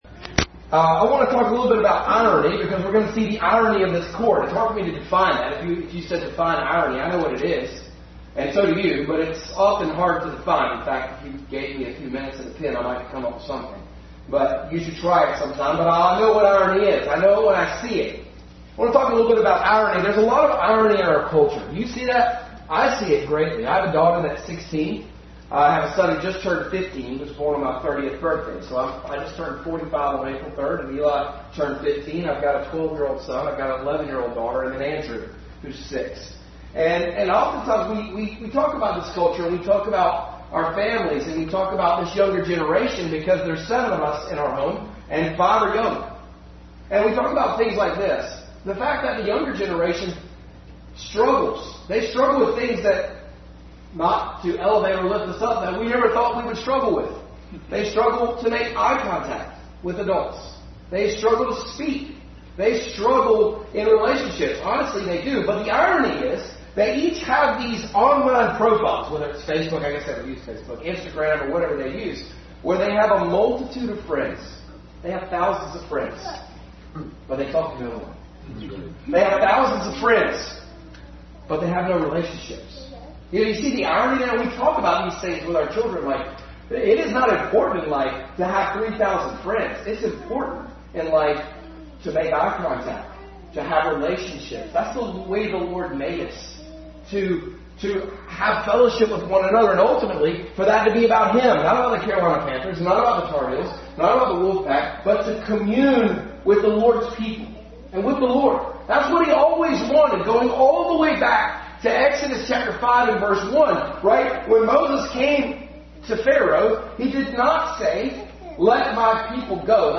Passage: Mark 14:53-65, 15:1 Service Type: Family Bible Hour